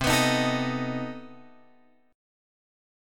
C Minor Major 13th